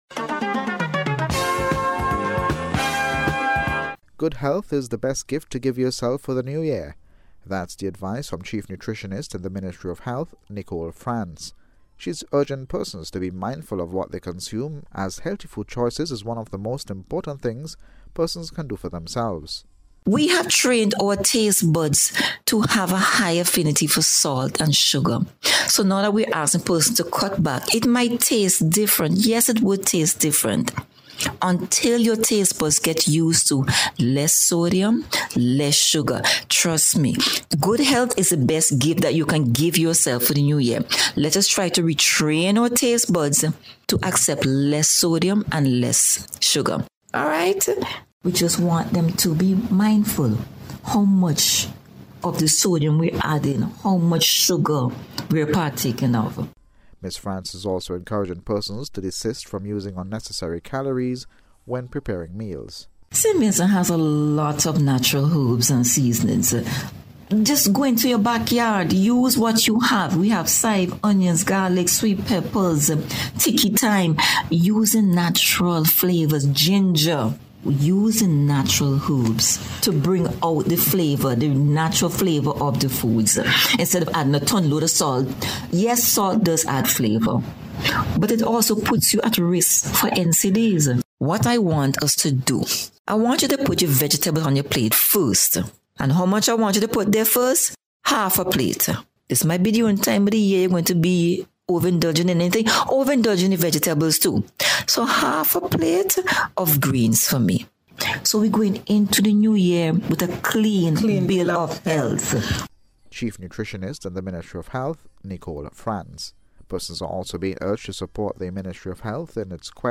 NBC’s Special Report – Wednesday December 27th 2023